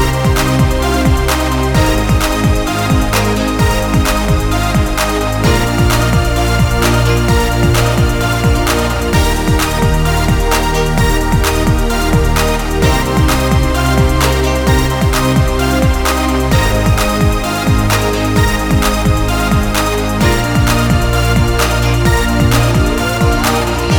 No Rapper Pop (2010s) 3:36 Buy £1.50